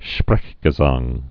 (shprĕgə-zäng)